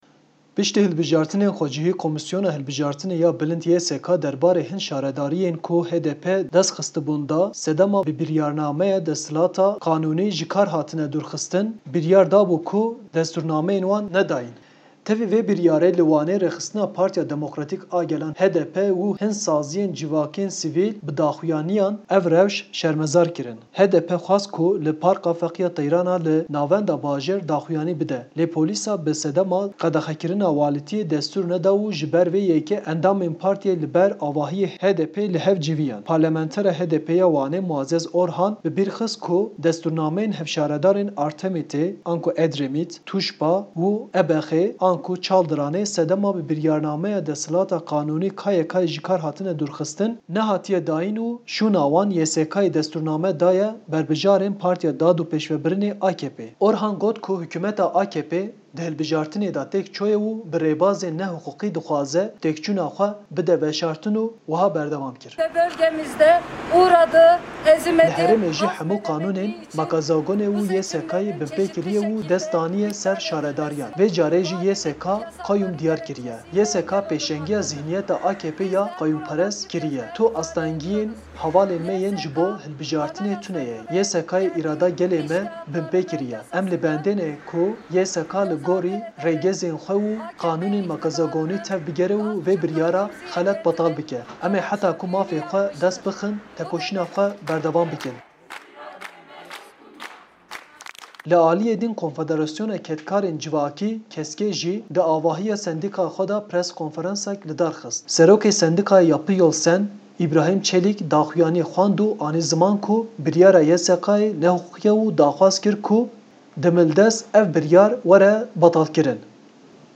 Protestoya gelê Wanê li dij betalkirina destrûrnameyên HDPê